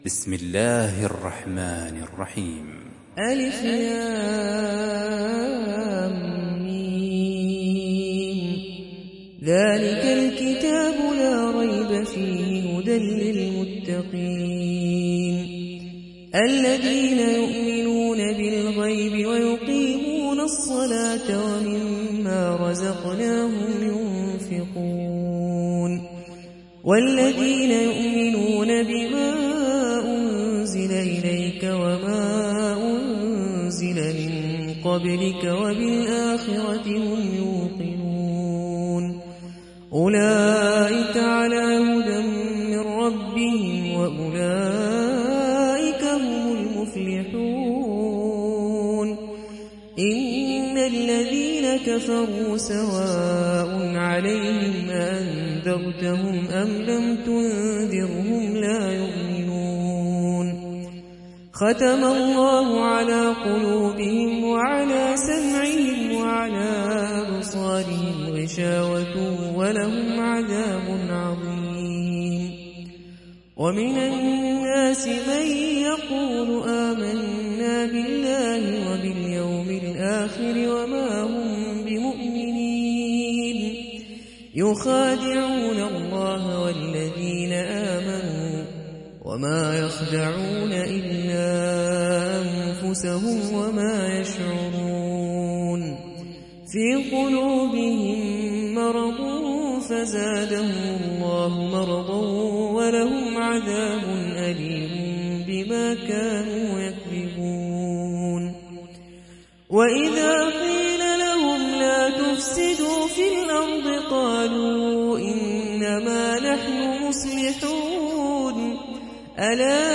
Riwayat Hafs an Asim